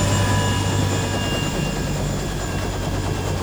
propellersStop.wav